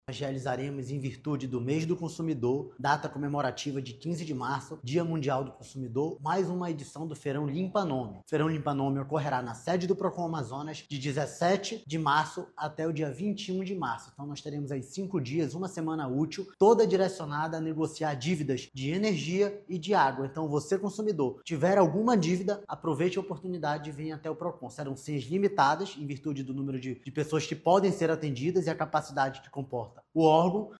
Serão disponibilizadas 300 senhas por dia para atendimento tanto da Águas de Manaus quanto da Amazonas Energia, como explica o diretor-presidente do Procon-AM, Jalil Fraxe.